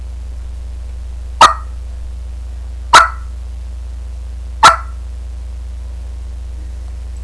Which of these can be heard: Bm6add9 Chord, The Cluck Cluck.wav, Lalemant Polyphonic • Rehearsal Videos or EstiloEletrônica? The Cluck Cluck.wav